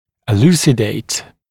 [ɪ’l(j)uːsɪdeɪt] [ə’l(j)uː-][и’л(й)у:сидэйт], [э’л(й)у:-]проливать свет, пояснять, разъяснять